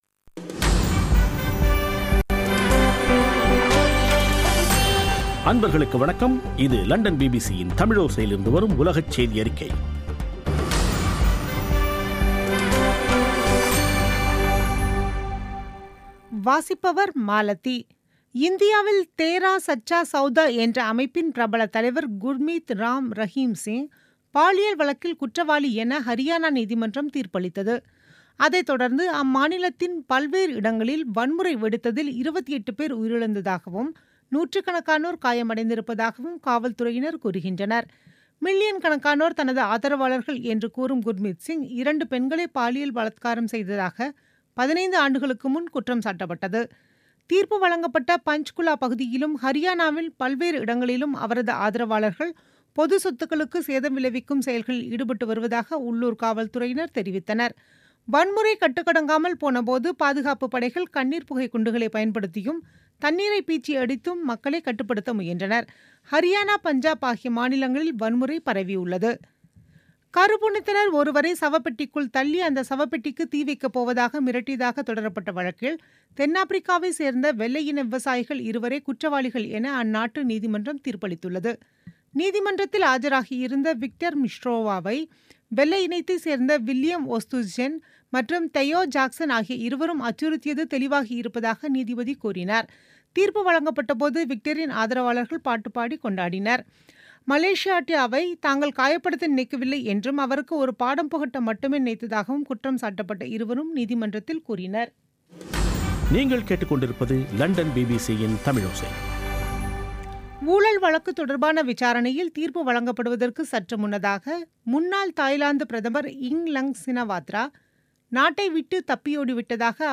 பிபிசி தமிழோசை செய்தியறிக்கை (25/08/2017)